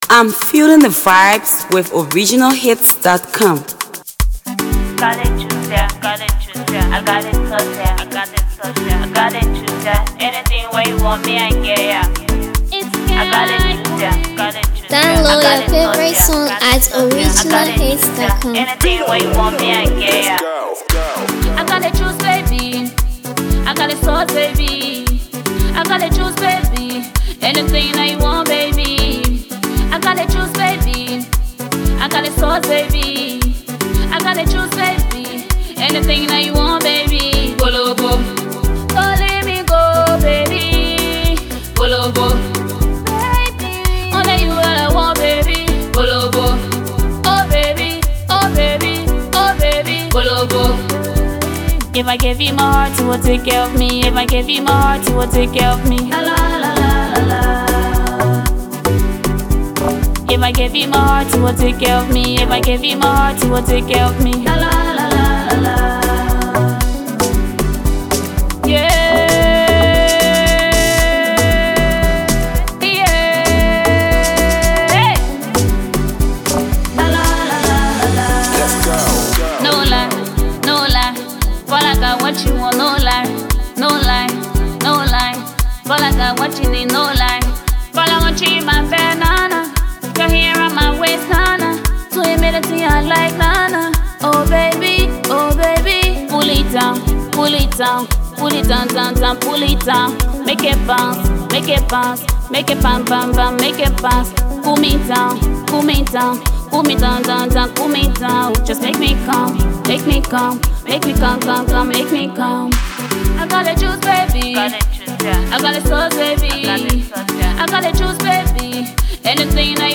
a fresh banger
hard-hitting, reality-driven track